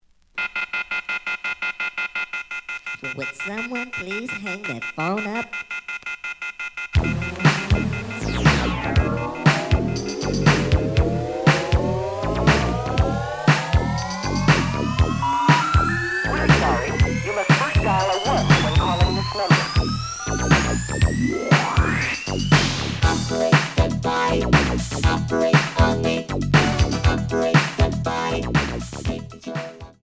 試聴は実際のレコードから録音しています。
●Genre: Disco
●Record Grading: EX- (盤に若干の歪み。多少の傷はあるが、おおむね良好。プロモ盤。)